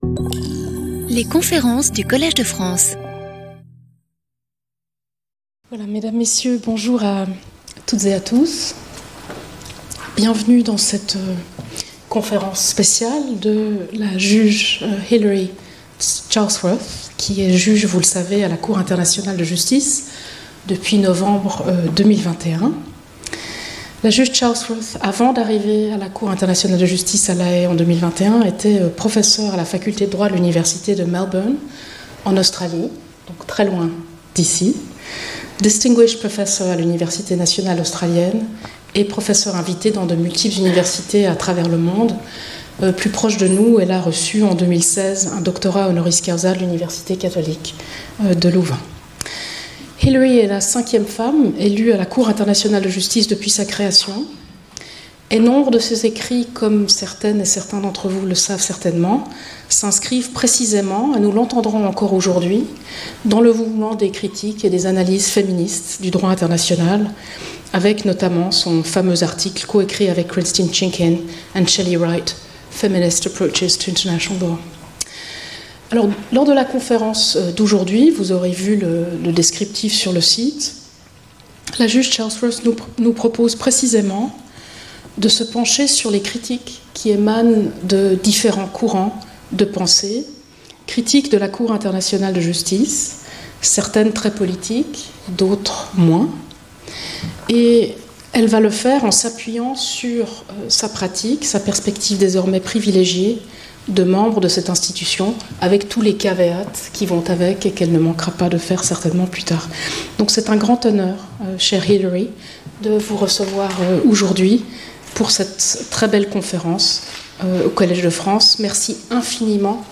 Cette conférence examinera certaines des critiques générales adressées à la Cour internationale de Justice et s’efforcera d’y répondre.